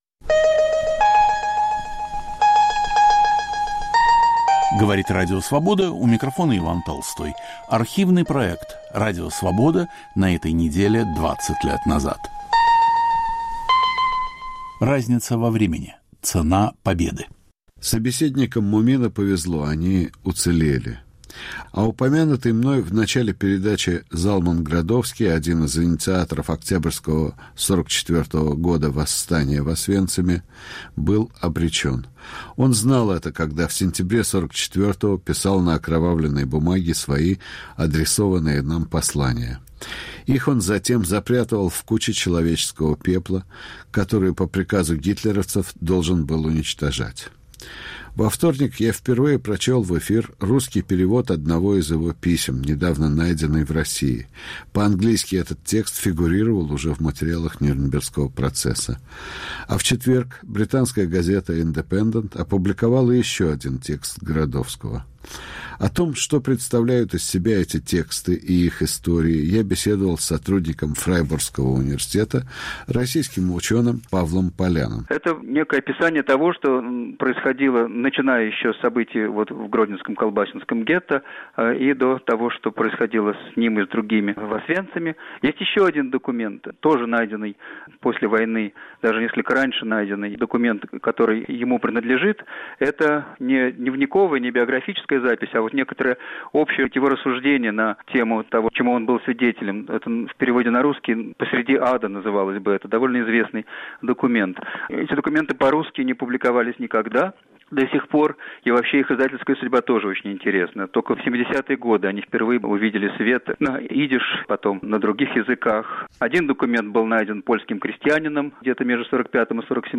Рассказывают выжившие заключенные.